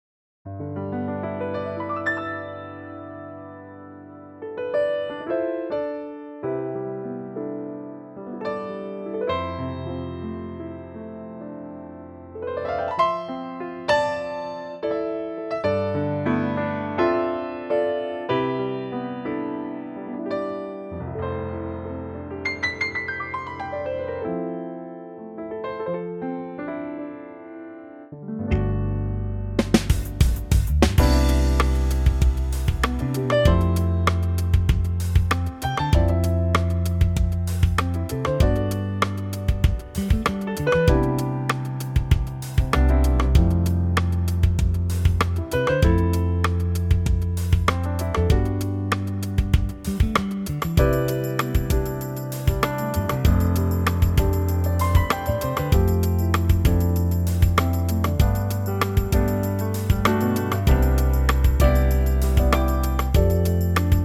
Unique Backing Tracks
key - G - vocal range - G to A ( optional C top note)